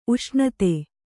♪ uṣṇate